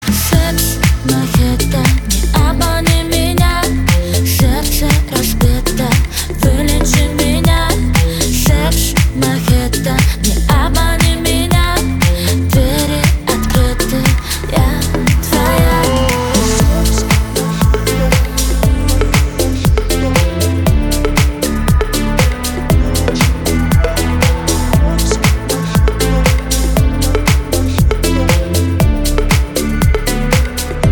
поп
ритмичные
женский вокал
спокойные
дуэт